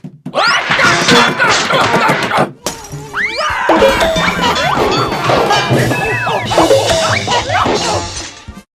Звуки падения по лестнице